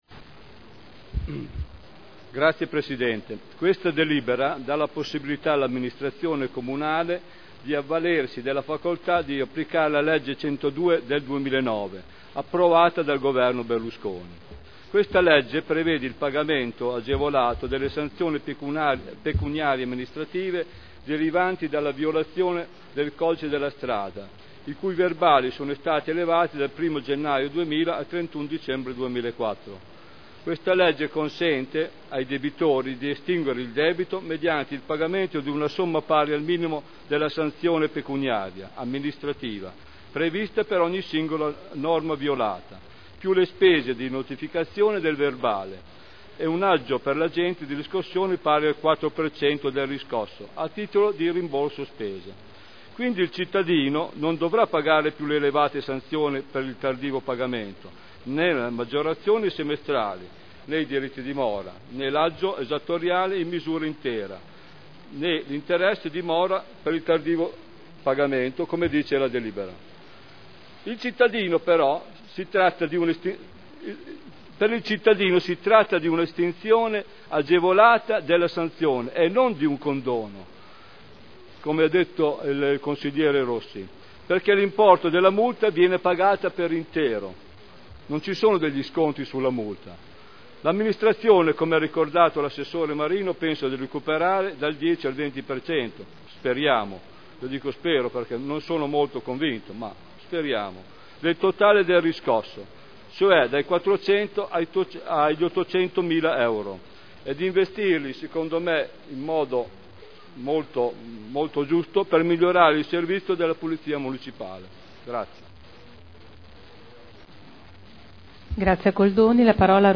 Stefano Goldoni — Sito Audio Consiglio Comunale
Seduta del 20/12/2010.